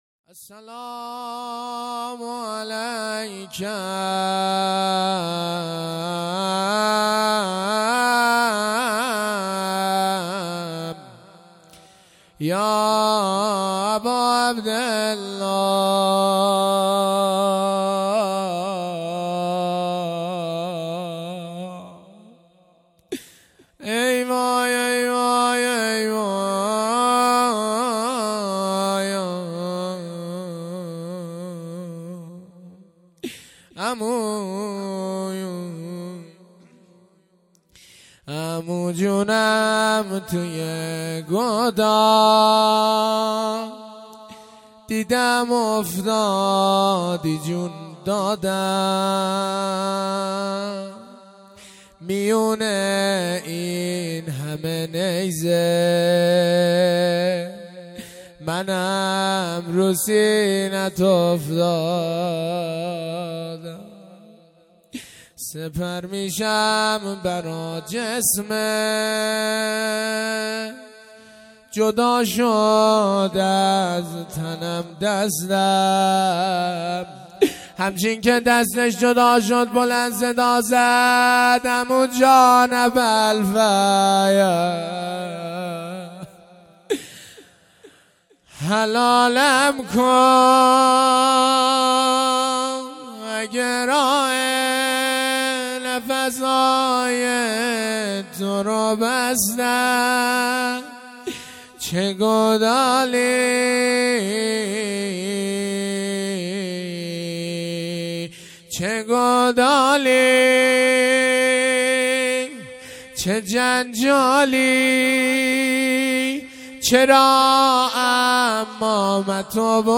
0 0 روضه